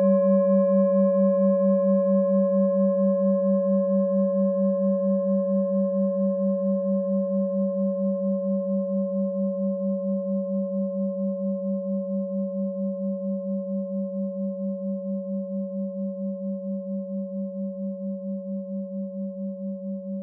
Klangschale Bengalen Nr.10
Klangschale-Gewicht: 990g
Klangschale-Durchmesser: 18,8cm
Sie ist neu und wurde gezielt nach altem 7-Metalle-Rezept in Handarbeit gezogen und gehämmert.
(Ermittelt mit dem Filzklöppel)
Wenn man die Frequenz des Mittleren Sonnentags 24mal oktaviert, hört man sie bei 194,18 Hz.
Auf unseren Tonleiter entspricht er etwa dem "G".
klangschale-ladakh-10.wav